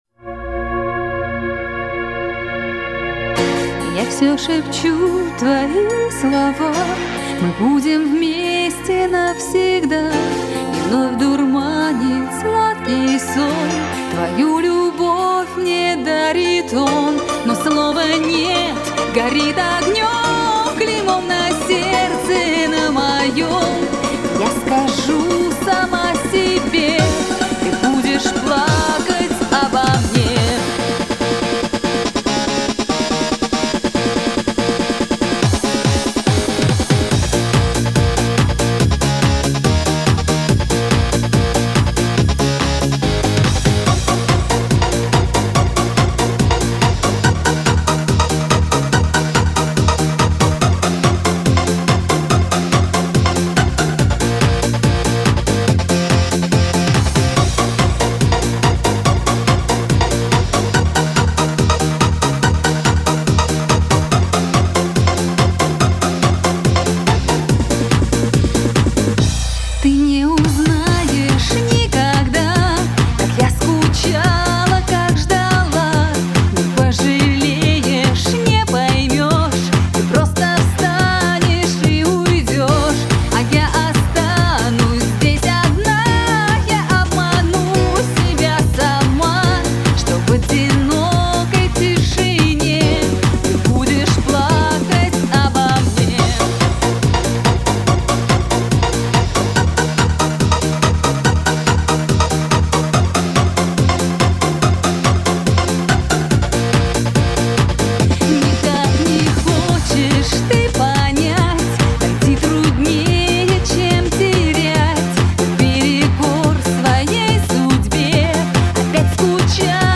это эмоциональная баллада в жанре поп